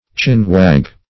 chin-wag - definition of chin-wag - synonyms, pronunciation, spelling from Free Dictionary